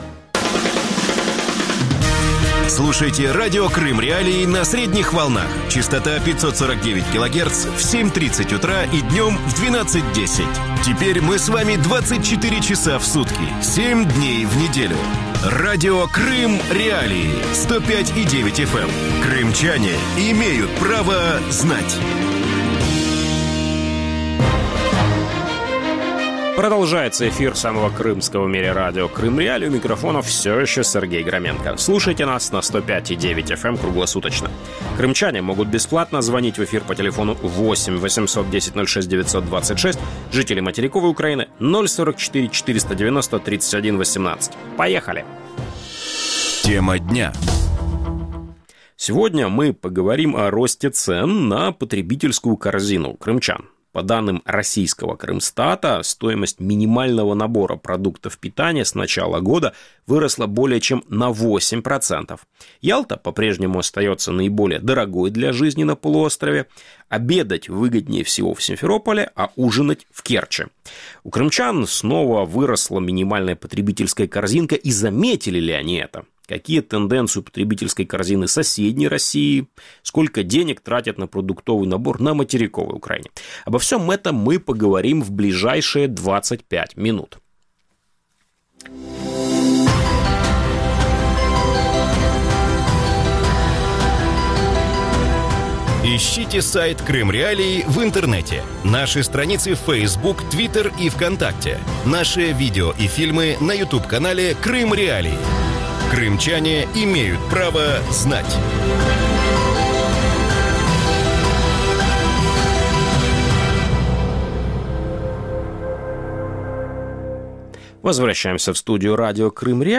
Радио Крым.Реалии в эфире 24 часа в сутки, 7 дней в неделю.